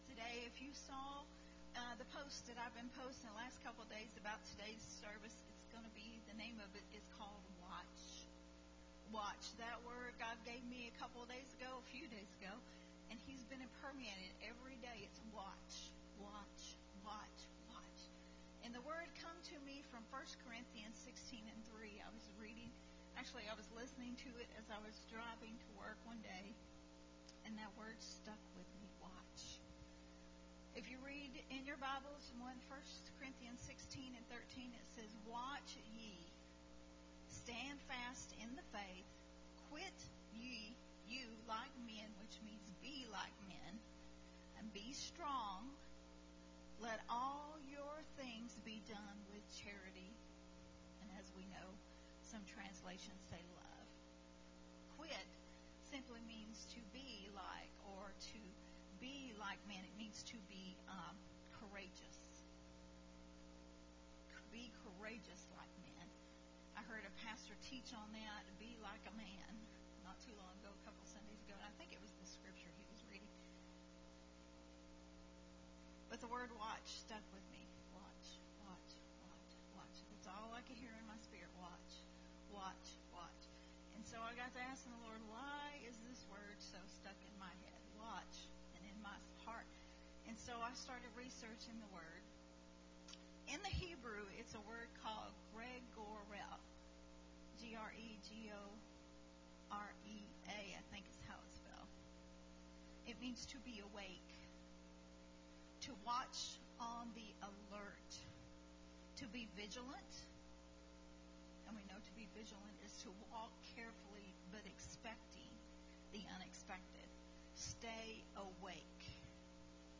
recorded at the Unity Worship Center on May 15th, 2022.